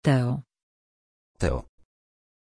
Pronuncia di Teo
pronunciation-teo-pl.mp3